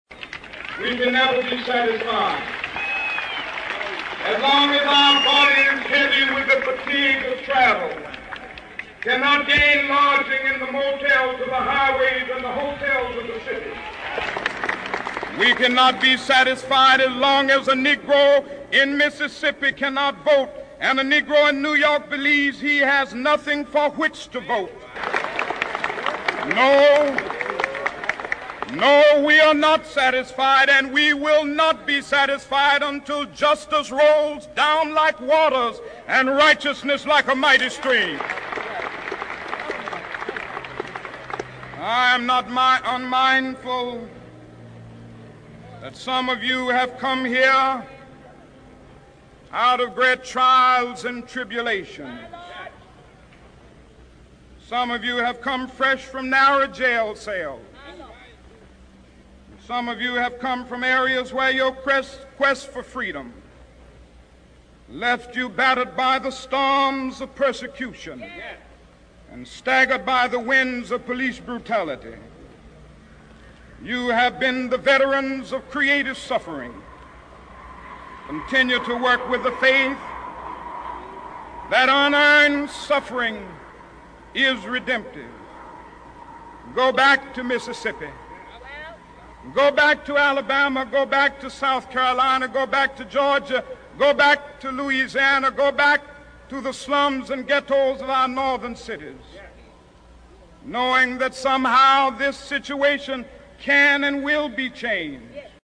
名人励志英语演讲 第63期:我有一个梦想(6) 听力文件下载—在线英语听力室